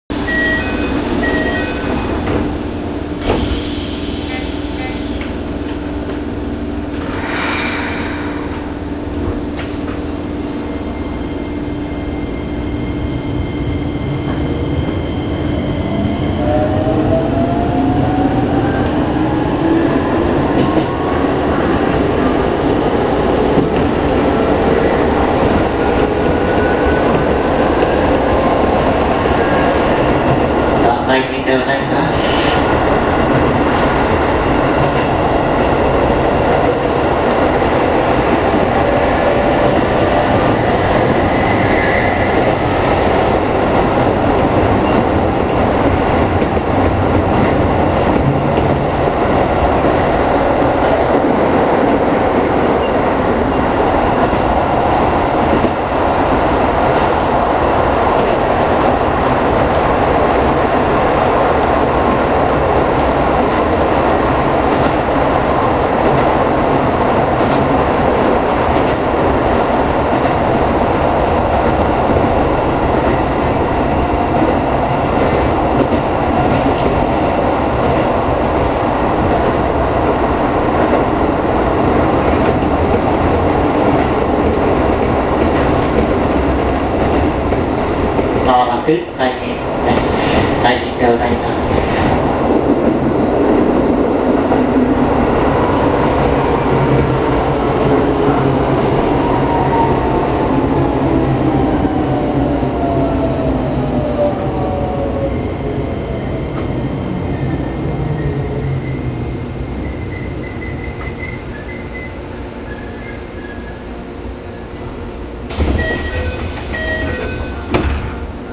・3000形走行音
【京成本線】船橋〜海神（1分58秒：644KB）